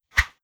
Close Combat Swing Sound 60.wav